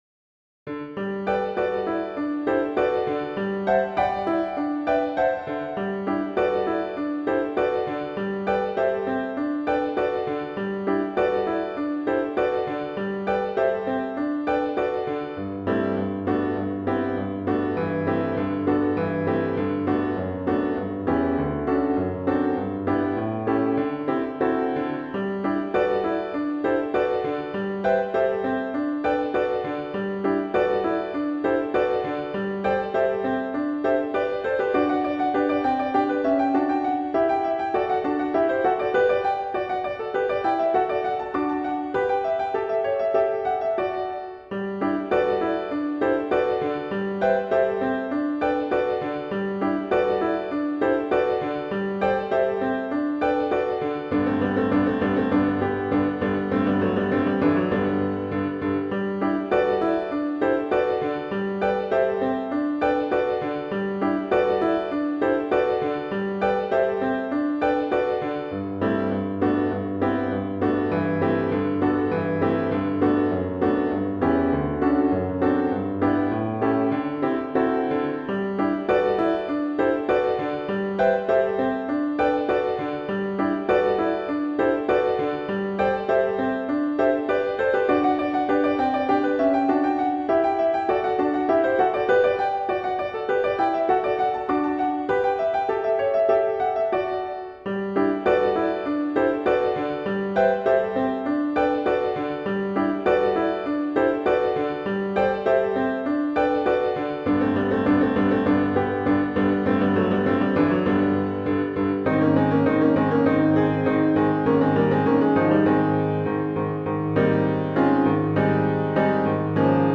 – Accompaniment Track – arr. Robert Sieving